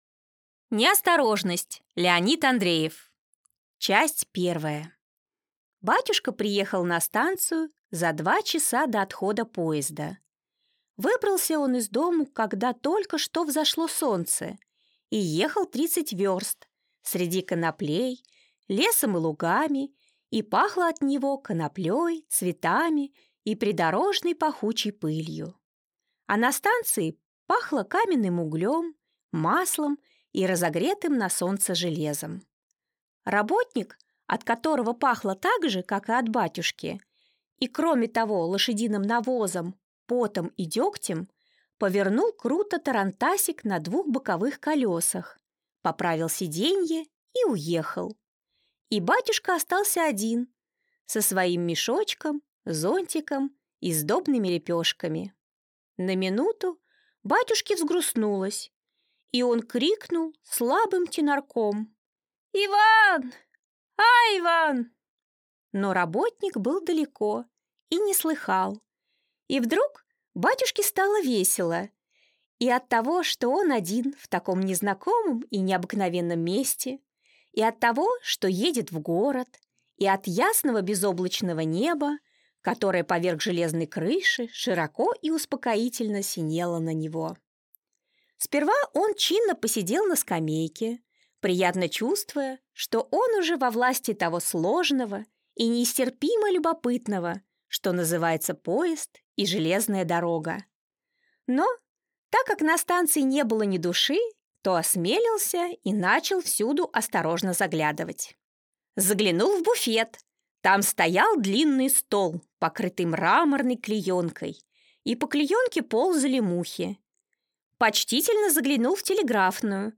Аудиокнига Неосторожность | Библиотека аудиокниг